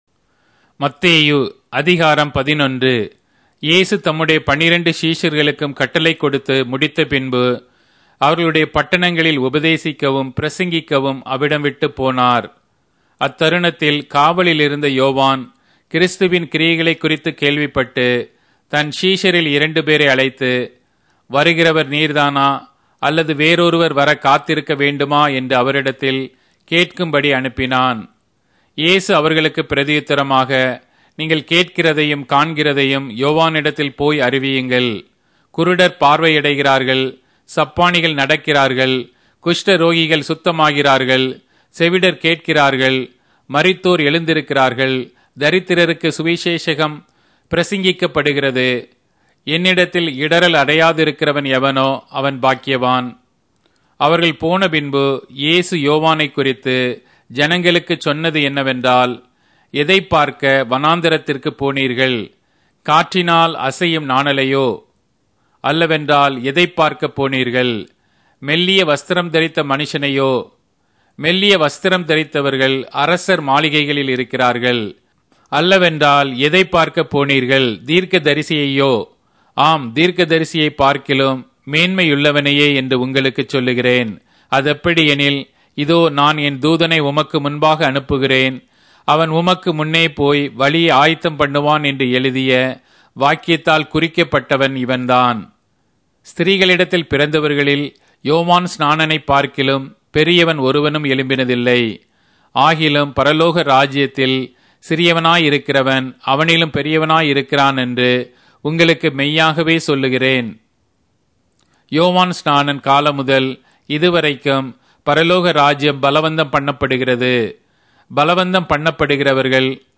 Tamil Audio Bible - Matthew 5 in Nlt bible version